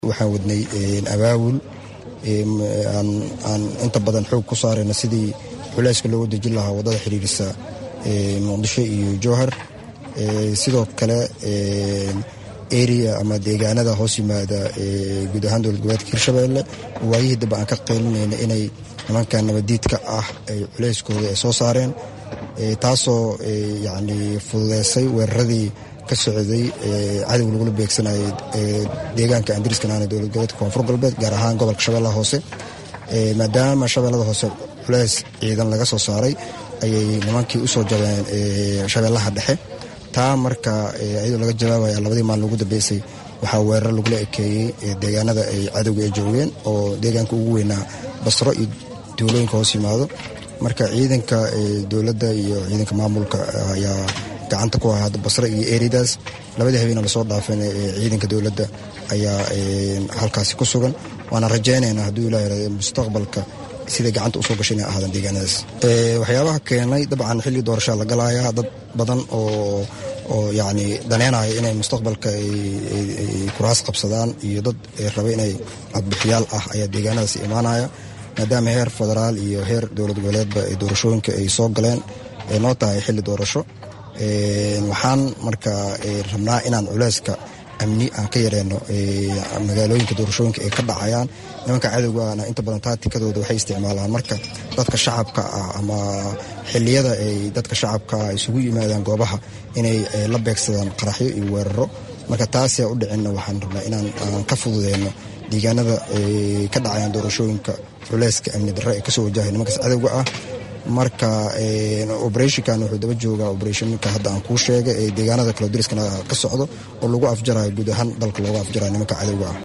Wasiirka Amniga Maamulka Hirshabeele Maxamed Cabdiraxmaan Kheyre ayaa Faah Faahin ka bixiyay Hawlgalo ka socda Wadada Xiriira Jowhar iyo Balcad ee gobolka shabeelaha Dhexe